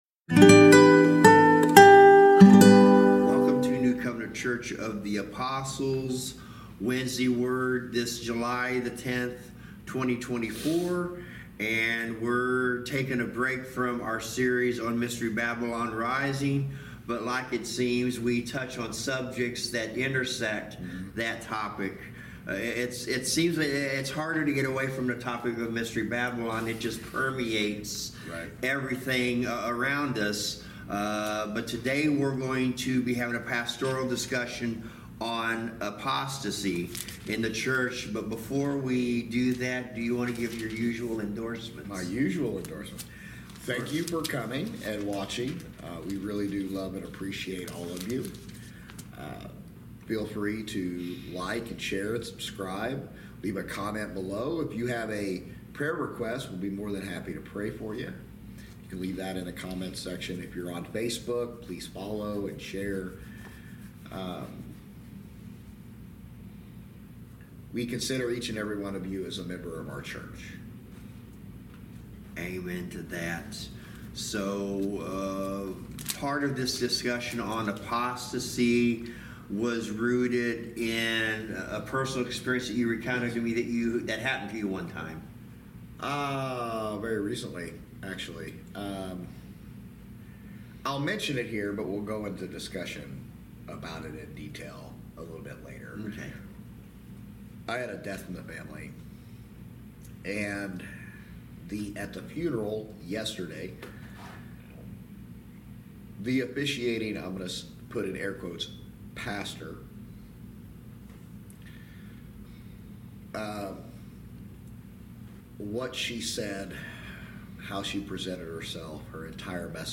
Service Type: Wednesday Word Bible Study
ApostasyAPastoralDiscussion.mp3